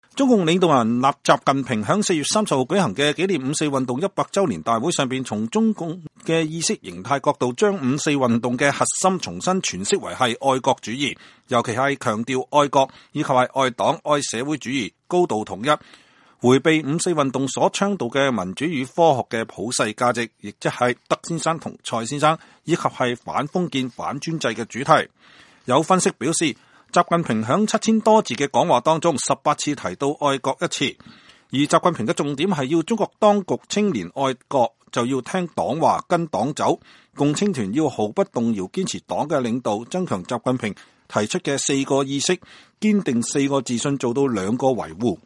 中共領導人習近平在4月30日舉行的紀念五四運動100週年大會上講話。